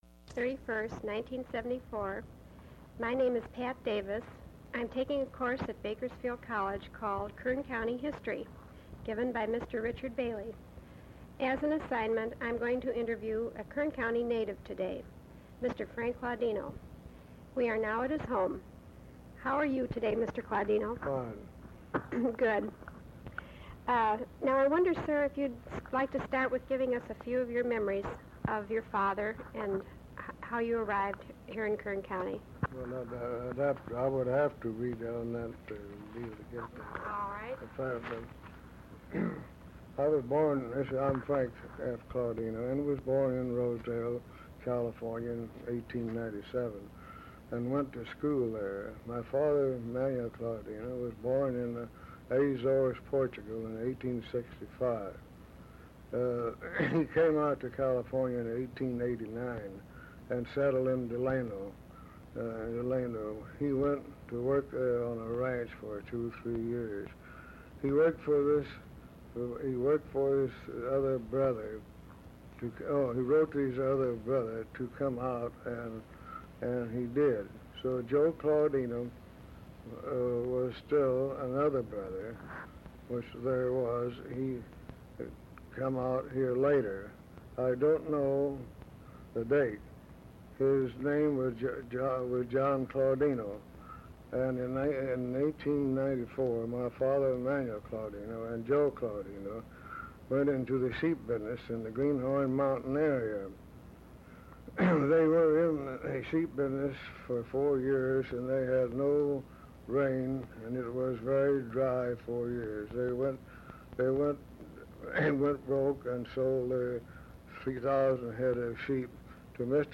Oral History Interview: Early Life in Bakersfield